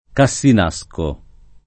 Cassinasco [ ka SS in #S ko ] top.